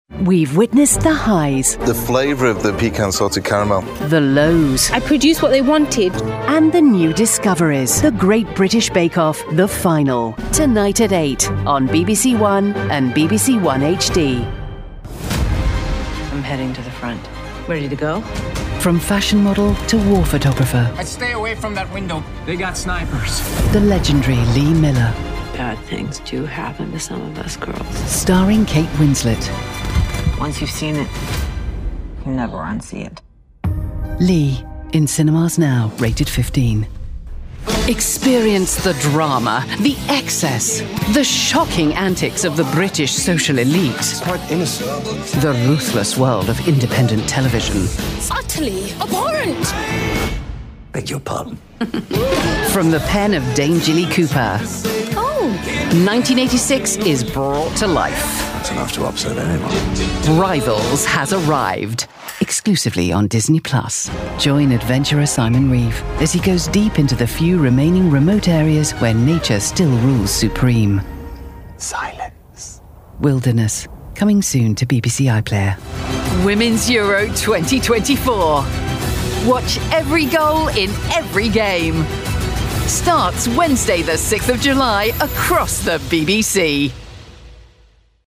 UK British Female Voiceover
FILM & TV TRAILS & PROMOS